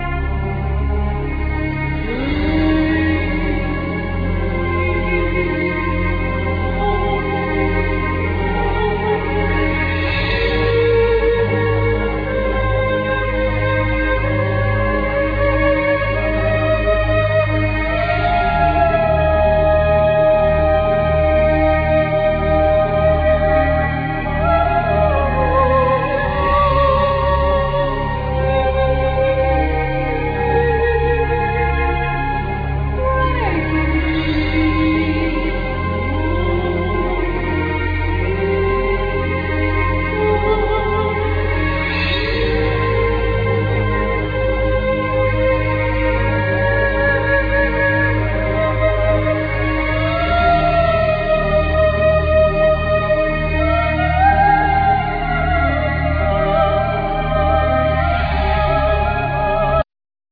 Keyboards, sing, percussions, flutes